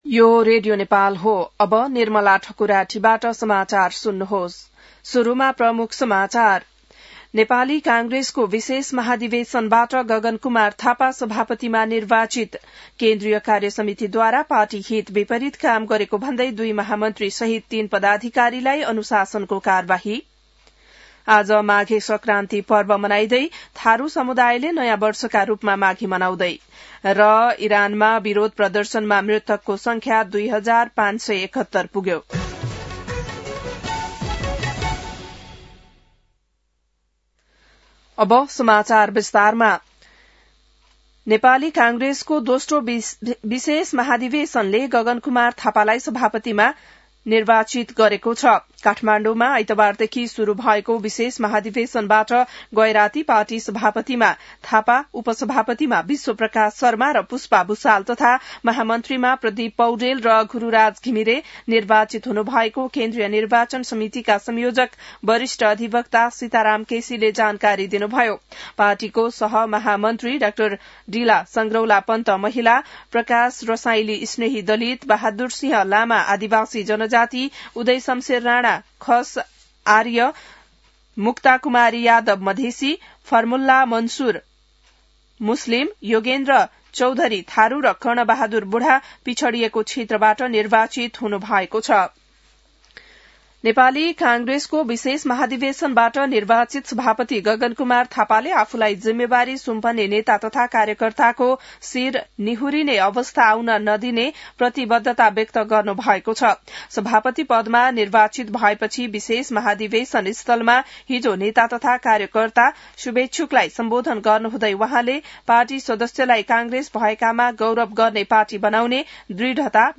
बिहान ९ बजेको नेपाली समाचार : १ माघ , २०८२